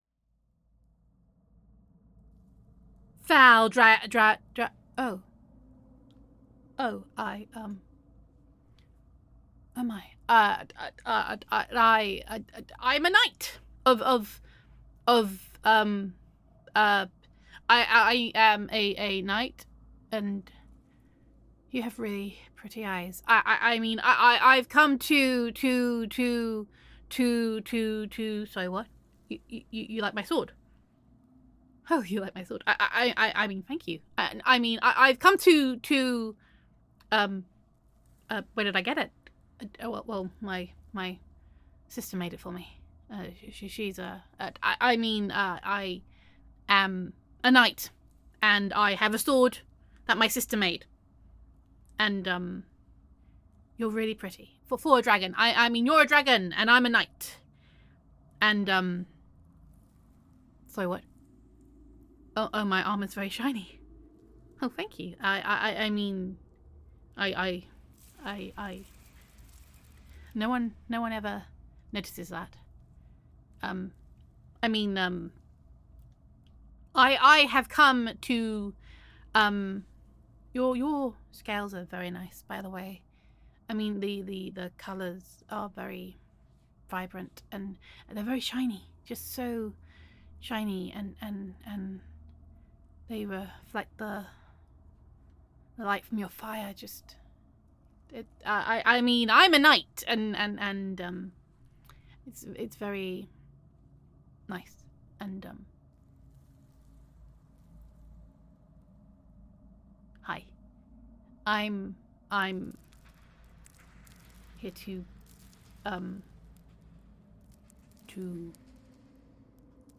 [F4A] A Comely Dragon Indeed [Instant Crush][Bumbling][Fantasy][Shy Knight][Dragon Listener][Gender Neutral][I Came to Slay You and Instead Fell in Love]